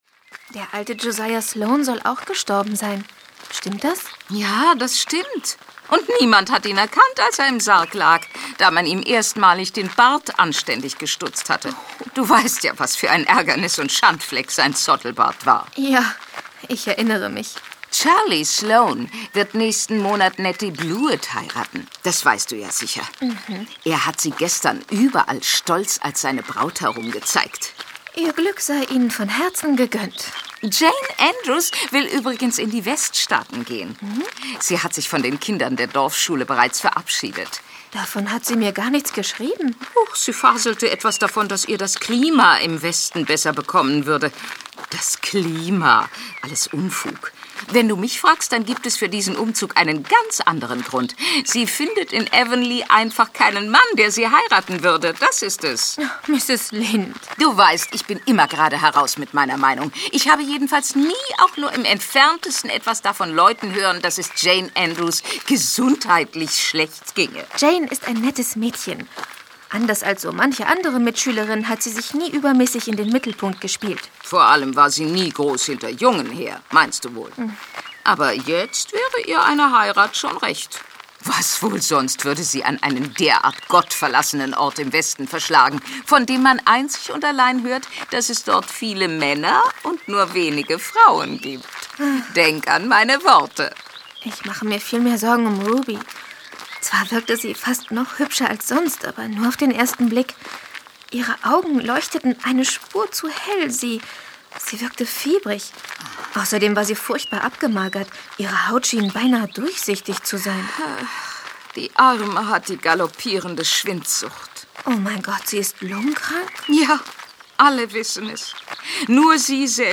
Anne in Kingsport - Folge 10 Erste Erfolge als Schriftstellerin. Hörspiel. L.M. Montgomery (Autor) Marie Bierstedt (Sprecher) Audio-CD 2009 | 3.